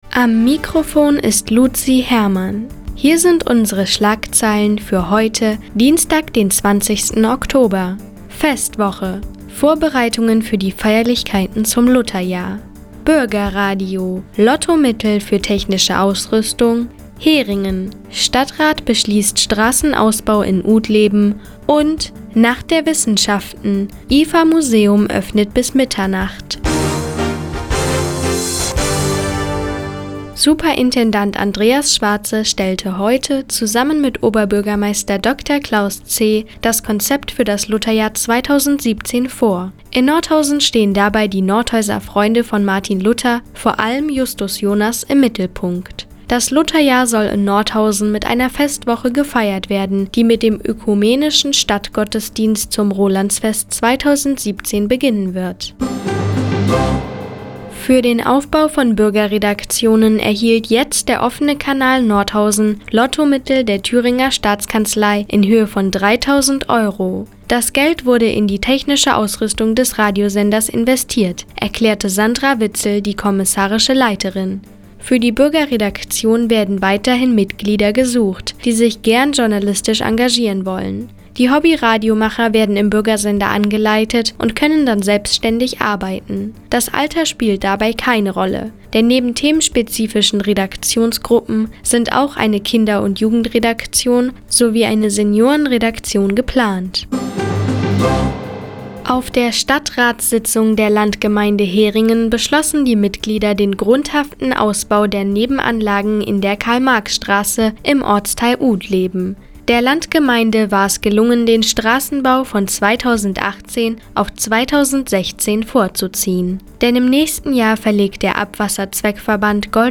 Die tägliche Nachrichtensendung des OKN ist jetzt hier zu hören...